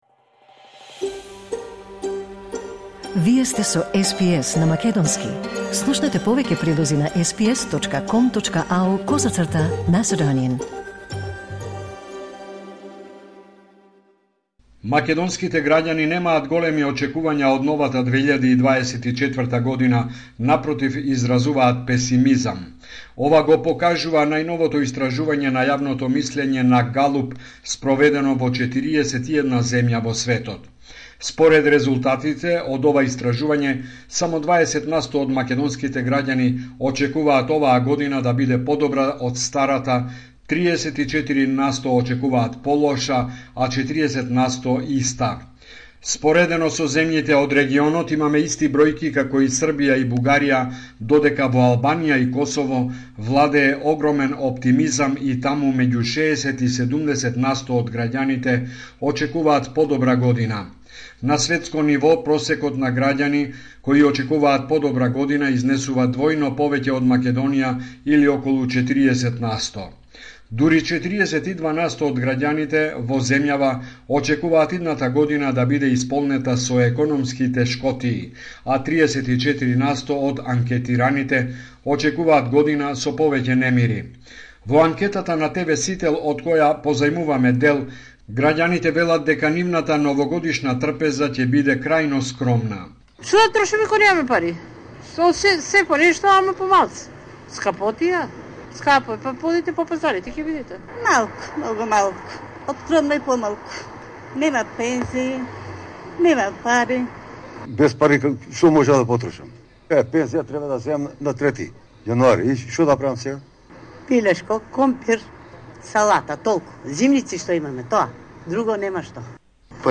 Homeland Report in Macedonian 1 January 2024